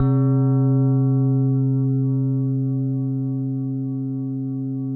WHINE  C1 -L.wav